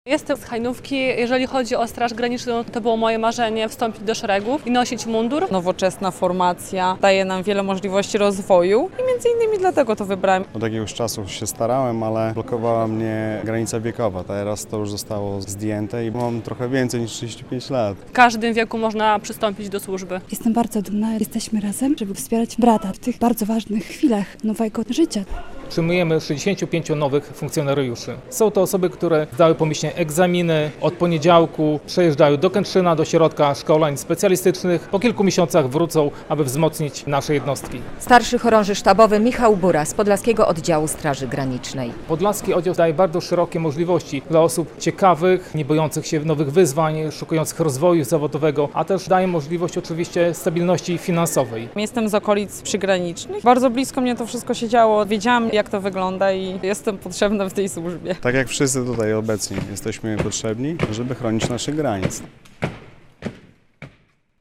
W uroczystej zbiórce w Białymstoku wzięły udział rodziny, przyjaciele i ślubujący funkcjonariusze.